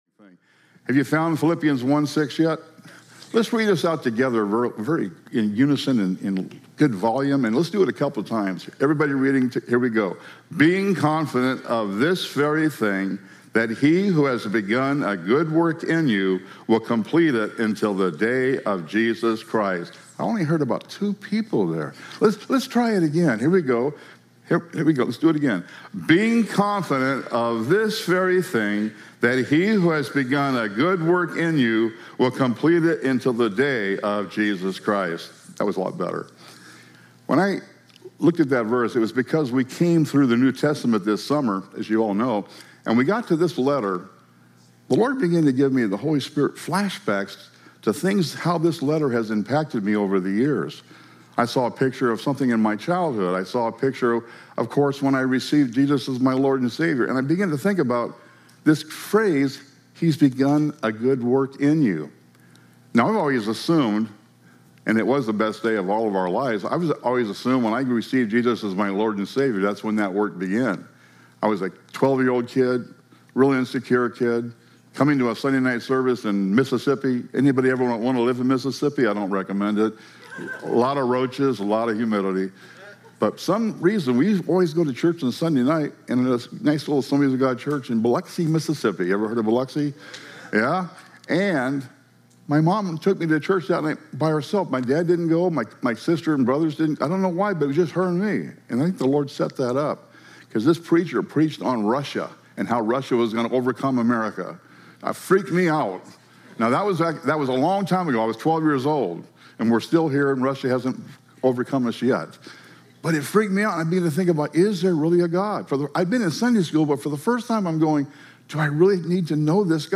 Topical Sermons